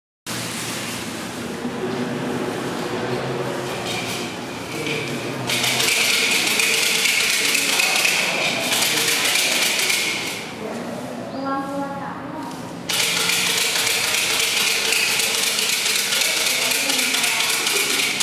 เสียงบรรยากาศ
เสียงบรรยากาศวัดฉลอง.mp3